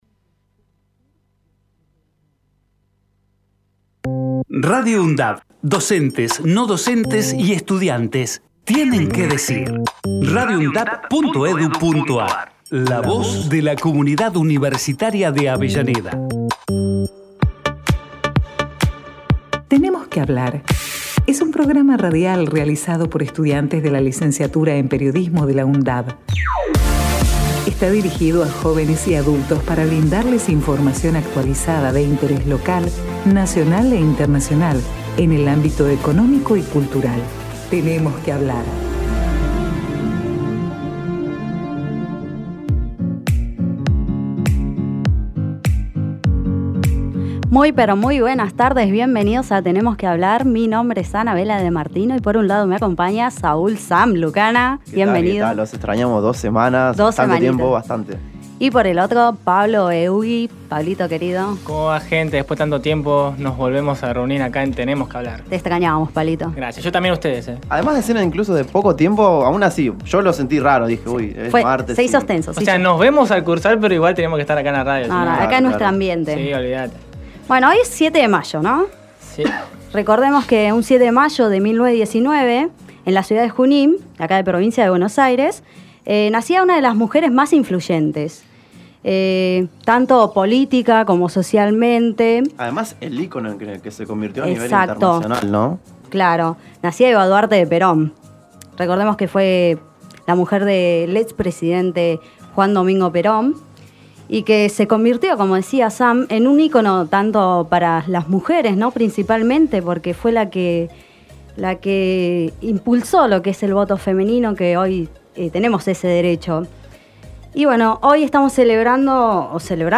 TENEMOS QUE HABLAR Texto de la nota: "Tenemos que hablar", es un programa radial hecho por estudiantes de la Licenciatura en Periodismo, de la Universidad Nacional de Avellaneda. Dirigido a jóvenes y adultos para brindarles información actual de interés Internacional, nacional, social, económico y cultural Archivo de audio: TENEMOS QUE HABLAR 2019-05-21.mp3 TENEMOS QUE HABLAR 2019-05-14.mp3 TENEMOS QUE HABLAR 2019-05-07.mp3 TENEMOS QUE HABLAR 2019-04-23.mp3 Programa: Tenemos Que Hablar